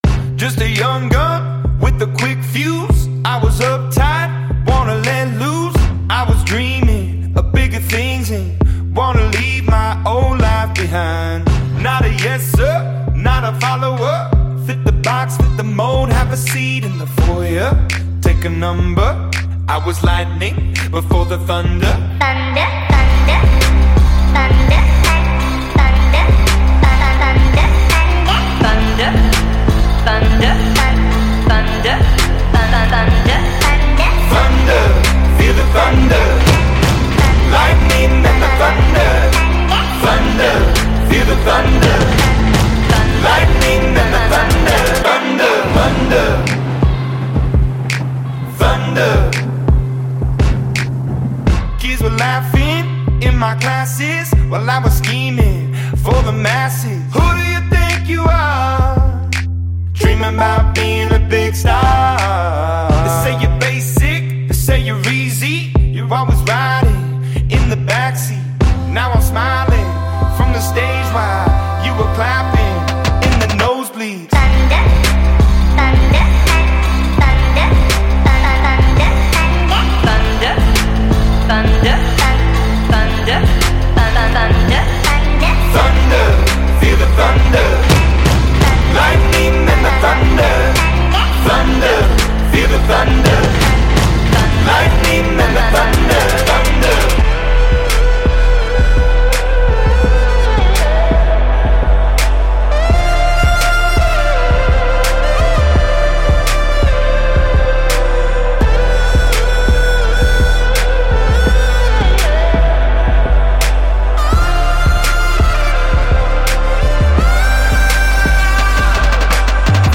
Rock 2010er